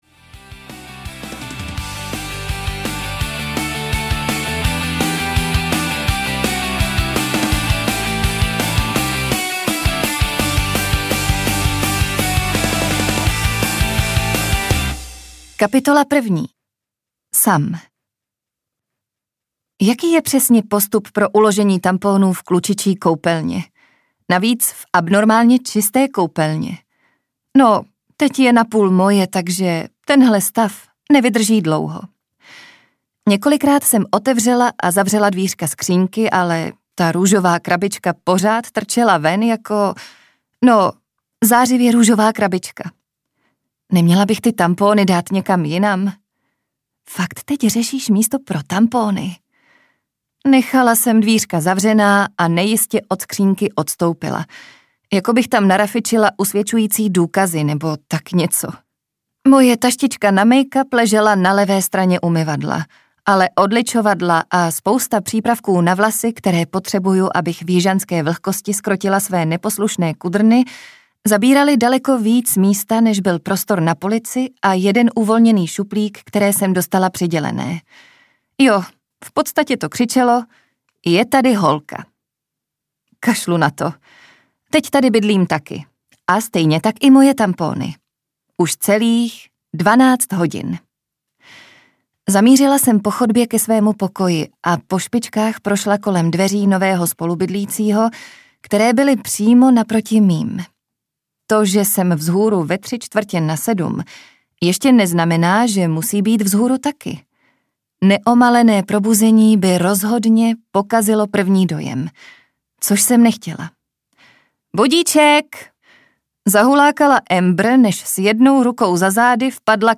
Za hranice lásky audiokniha
Ukázka z knihy